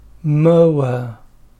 /əʊ/ + /ə/ =  [əʊə]
mower.mp3